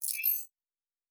Sci-Fi Sounds / Weapons
Additional Weapon Sounds 2_4.wav